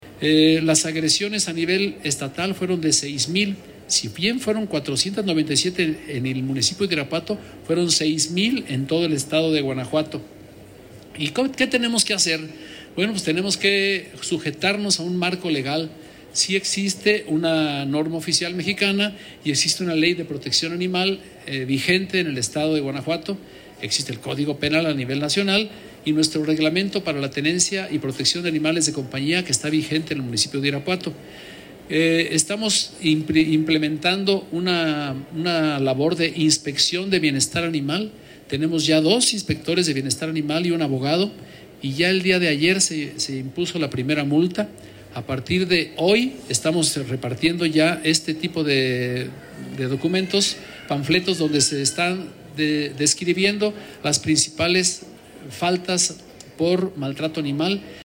AudioBoletines
Eduardo Tovar Guerrero, director de Salud